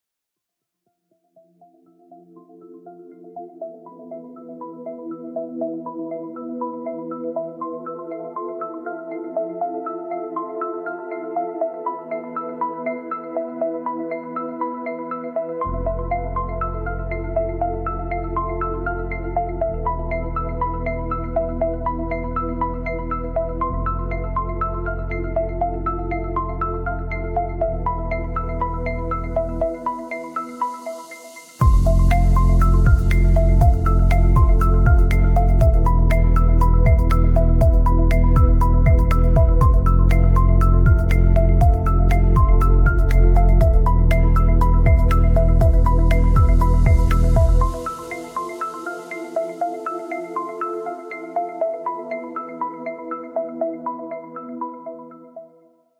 To make it sound like something, I've also created this timer-alert sound.